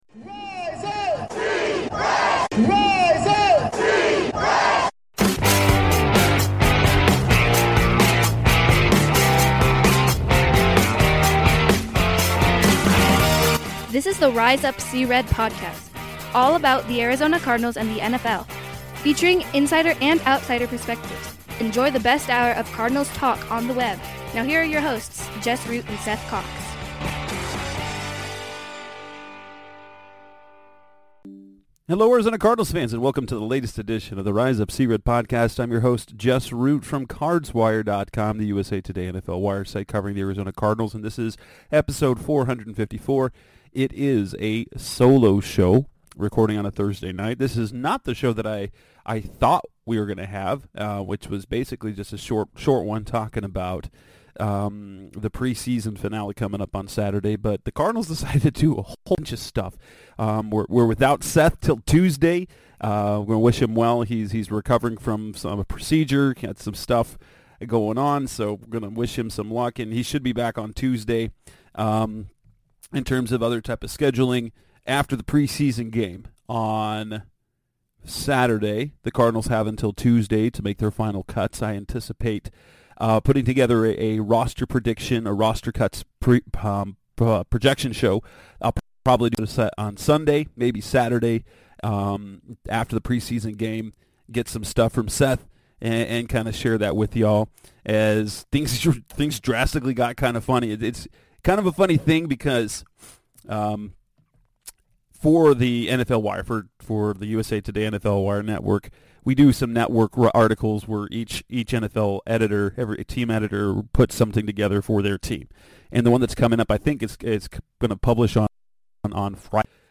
Before the Cardinals' final preseason game, they made three trades, acquiring QB Josh Dobbs and sending away OL Josh Jones and S Isaiah Simmons. In this solo show